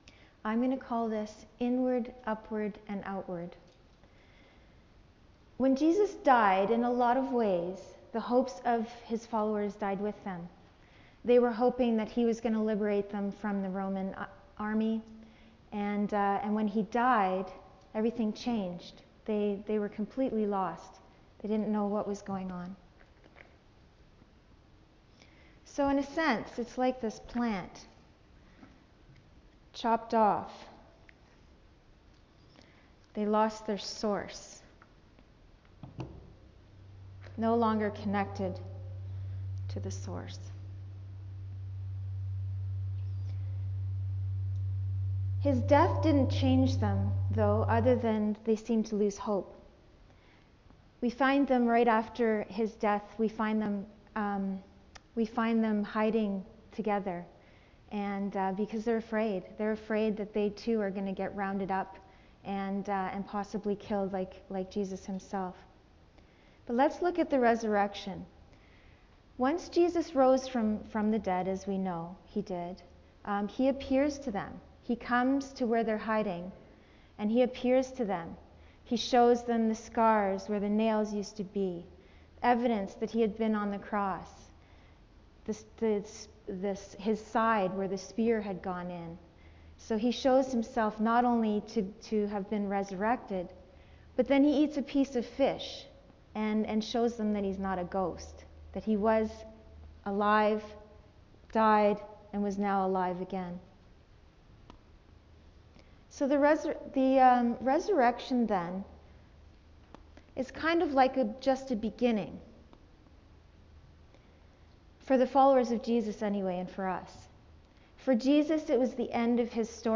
Acts 2:1-13 Service Type: Friday Nights Bible Text